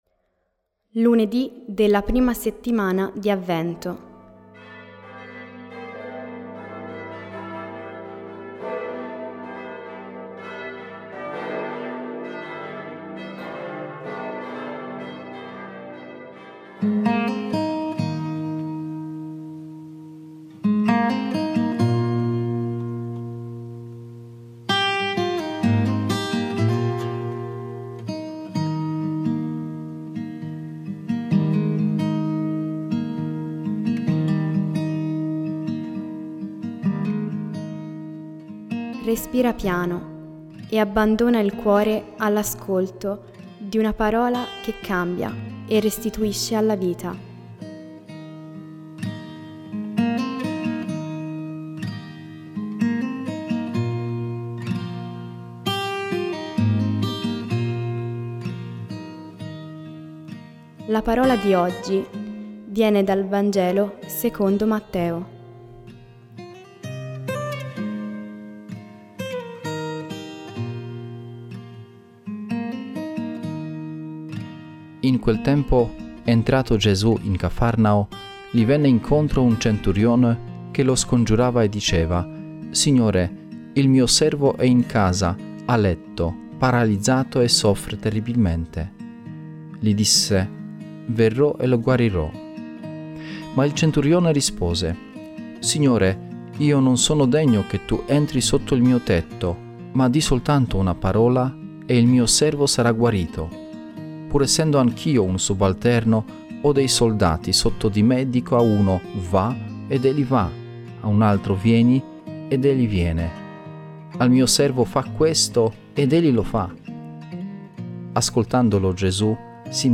Voci narranti